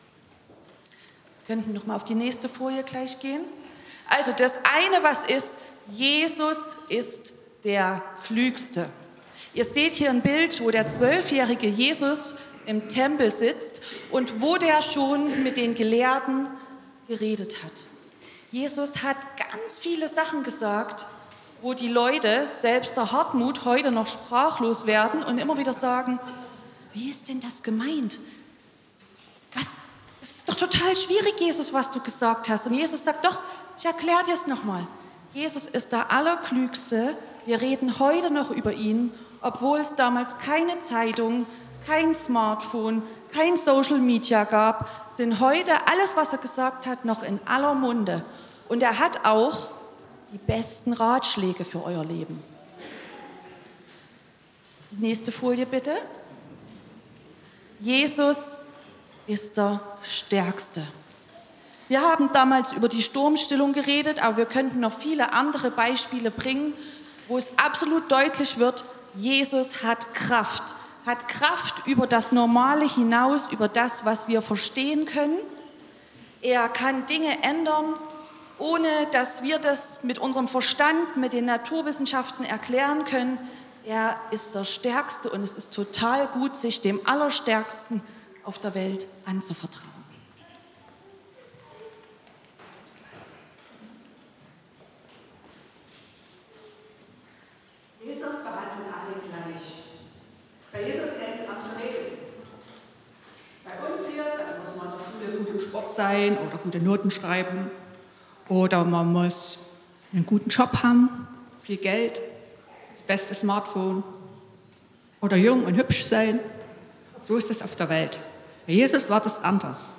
generationsübergreifender Lobpreisgottesdienst